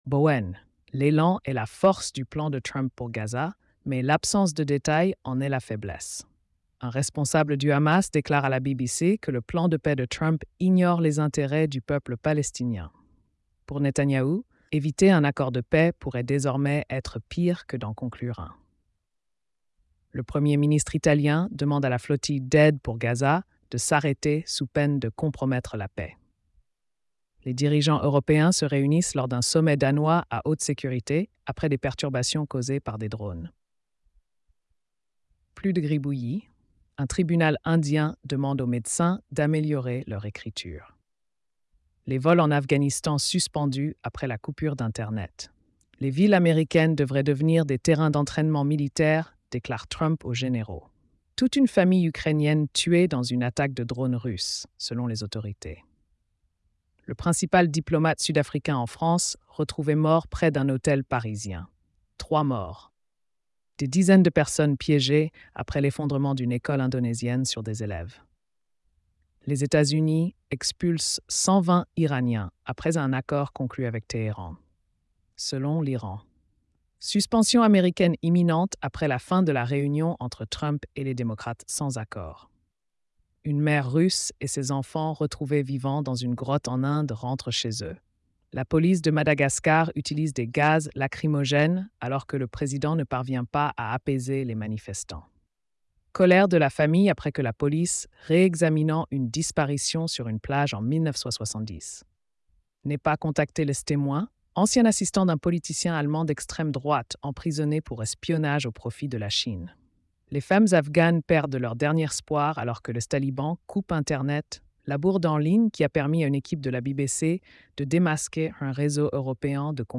🎧 Résumé des nouvelles quotidiennes.